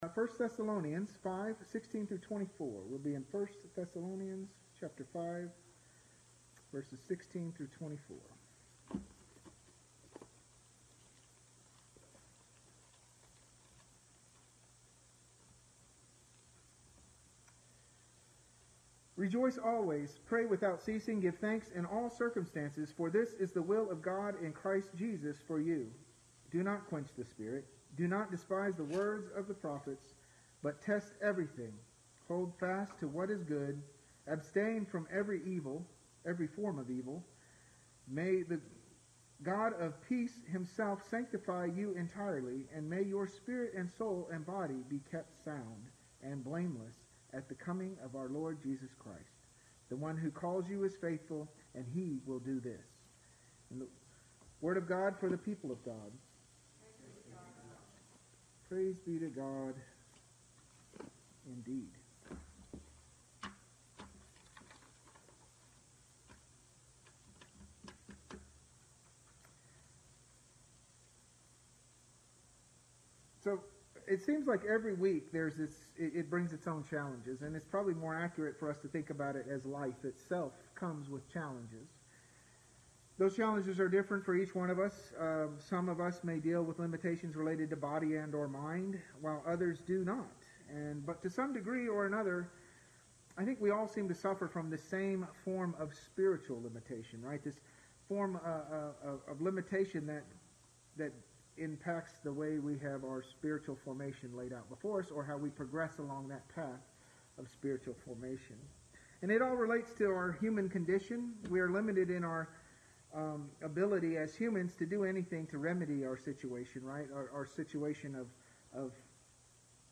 First United Methodist Church Kemp Sermons 2020